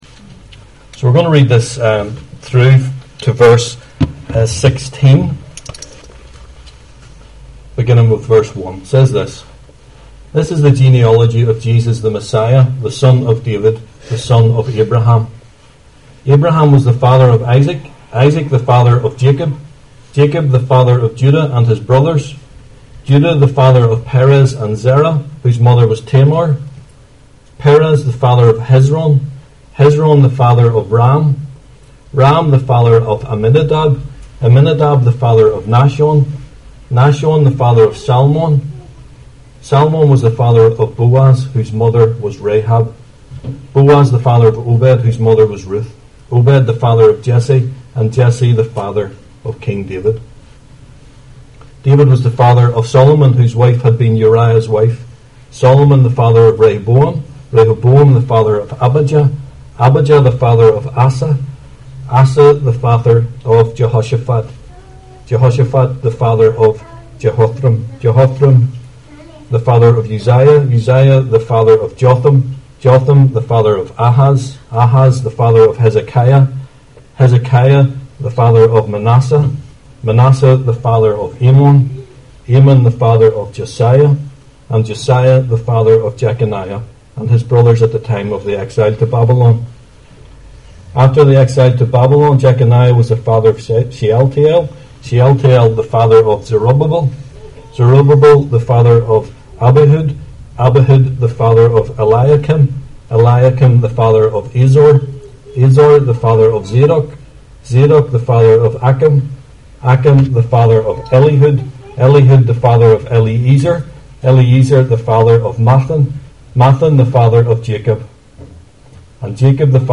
Service Type: 11am